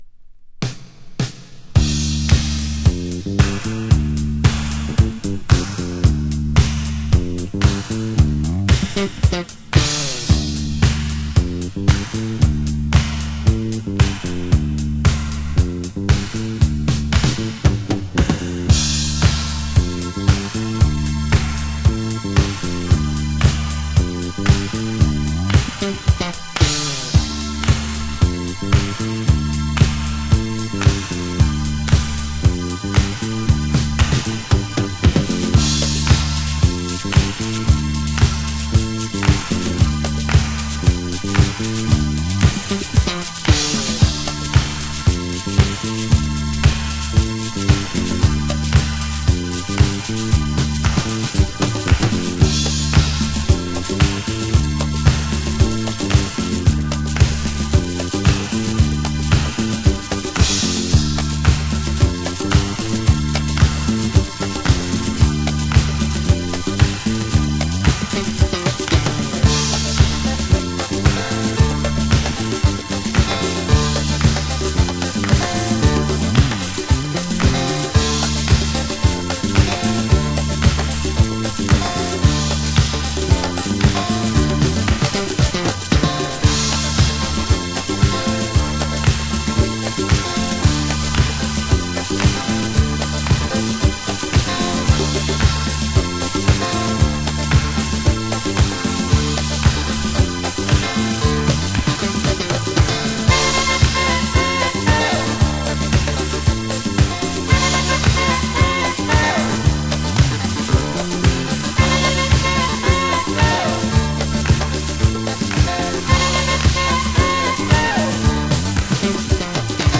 盤質：少しチリパチノイズ有/濃茶クリア盤